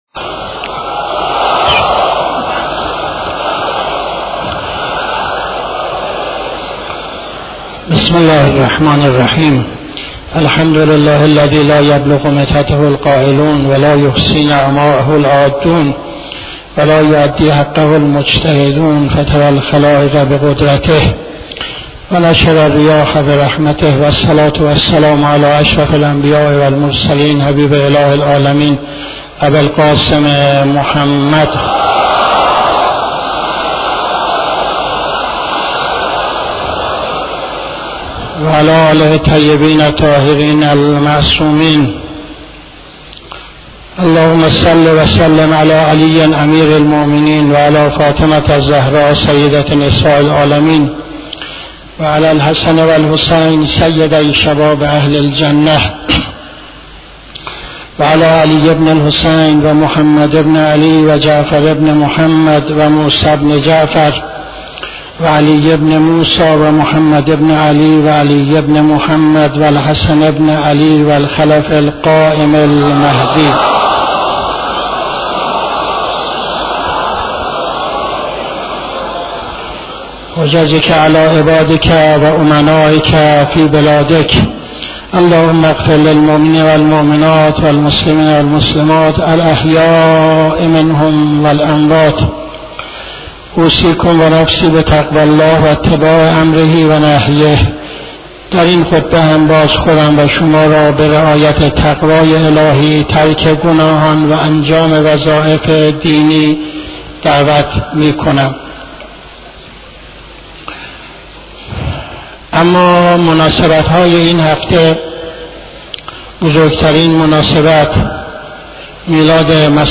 خطبه دوم نماز جمعه 29-08-77